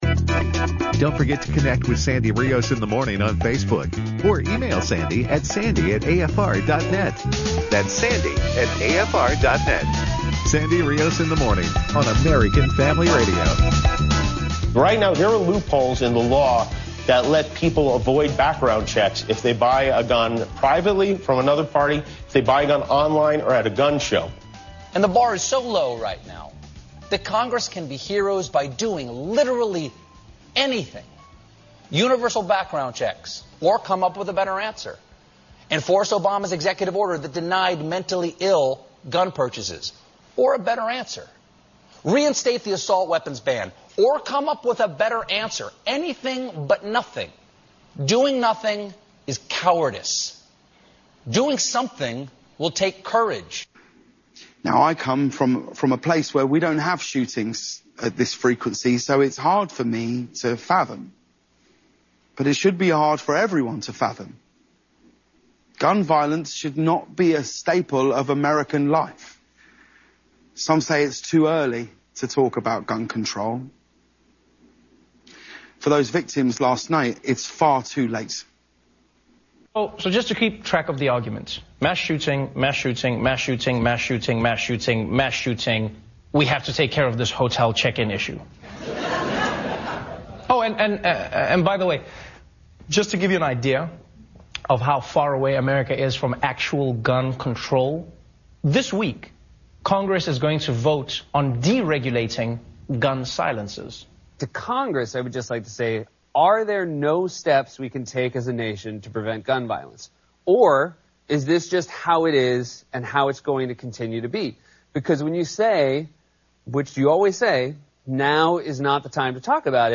Dr. John Lott talked to Sandy Rios on her national radio show to discuss the Las Vegas shooting and the misinformation put out by gun control advocates.